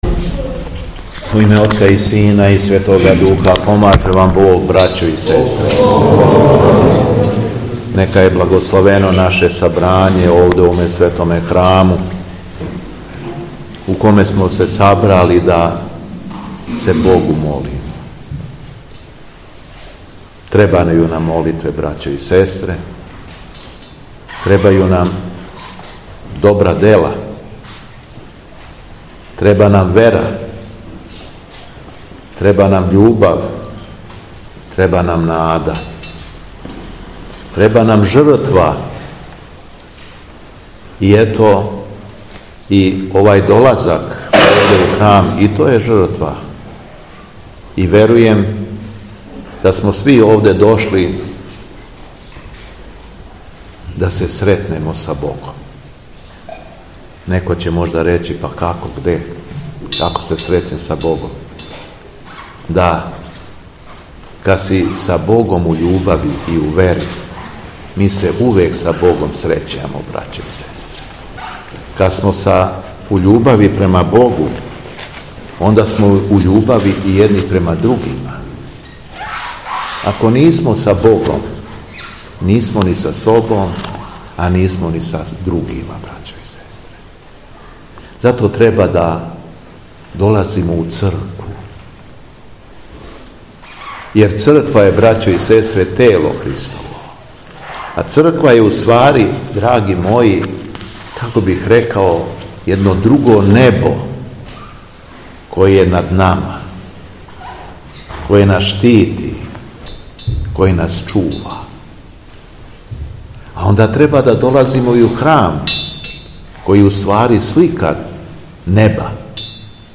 У суботу 31. маја 2025. године, када се наша Црква налази у периоду прославе попразништва Вазнесења Господњег, Његово Високопреосвештенство Архиепископ крагујевачки и Митрополит шумадијски Господин Јован, служио је Свету Архијерејску Литургију у селу Сиоковац, код Јагодине.
Беседа Његовог Високопреосвештенства Митрополита шумадијског г. Јована
Након прочитаног јеванђелског зачала, Митрополит Јован се обратио верном народу рекавши следеће: